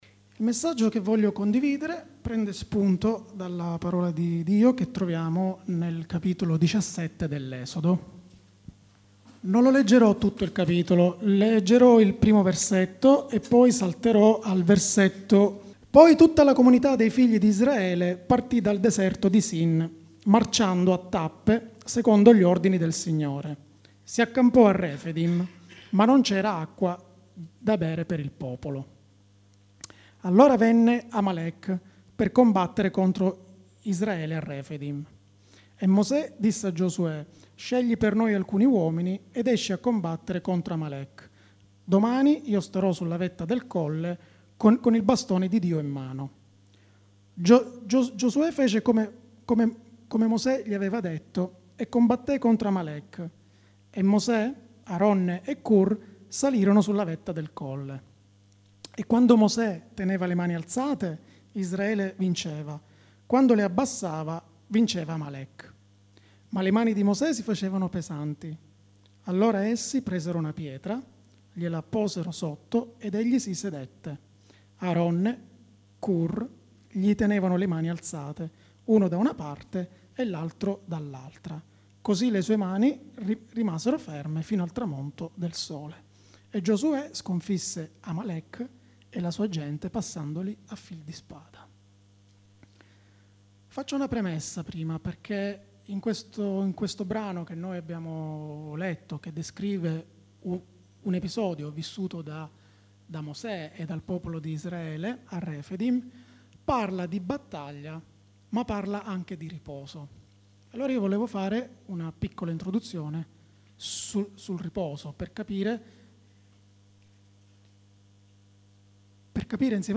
Siamo forti nel corpo di Cristo, buon ascolto! 0:00 ( Clicca qui se vuoi scaricare il file mp3 ) ‹ Il vero cristiano La famiglia › Pubblicato in Messaggio domenicale